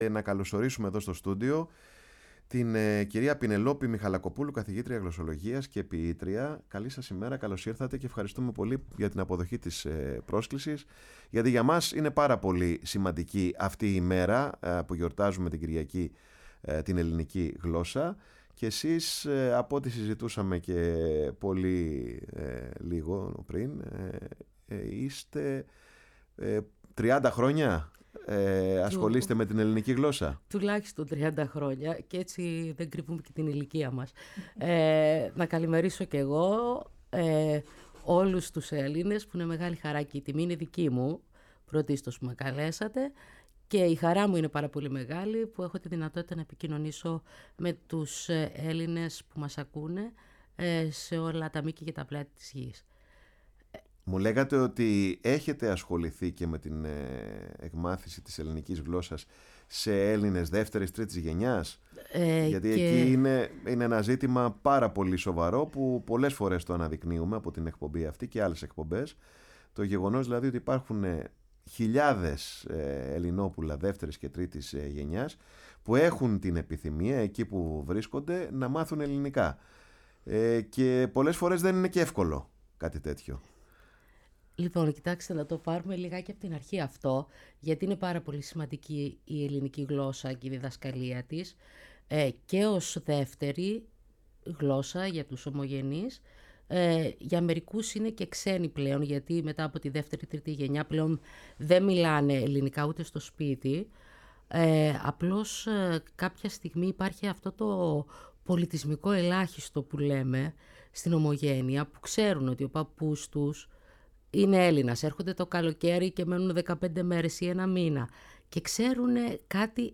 Η ΦΩΝΗ ΤΗΣ ΕΛΛΑΔΑΣ Παρε τον Χρονο σου ΣΥΝΕΝΤΕΥΞΕΙΣ